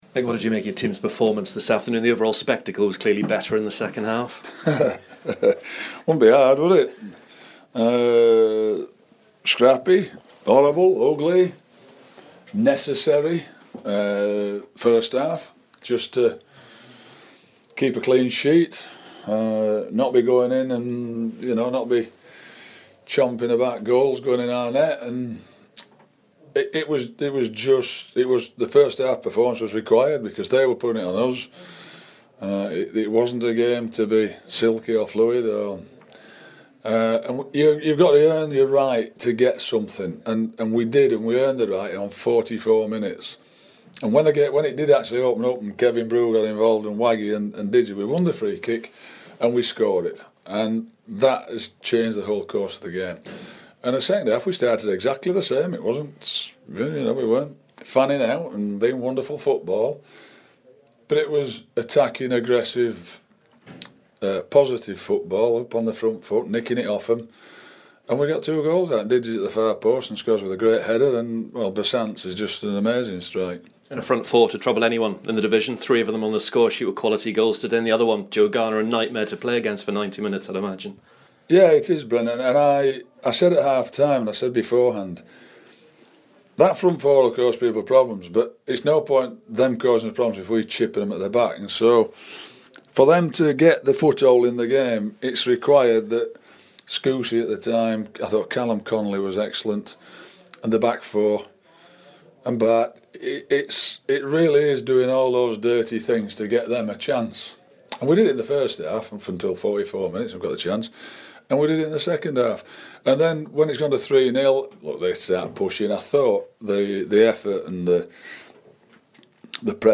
Blues boss Mick McCarthy